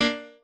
piano3_35.ogg